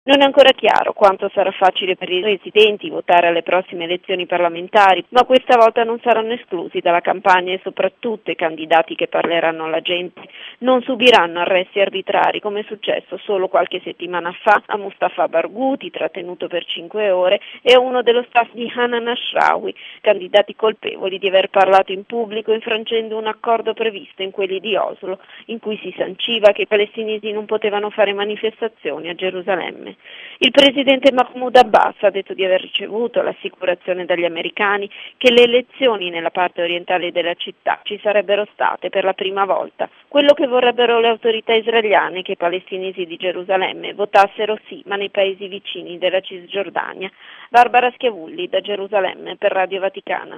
E sulla decisione israeliana di consentire ai candidati palestinesi di svolgere la campagna elettorale in vista delle consultazioni del 25 gennaio prossimo ascoltiamo, da Gerusalemme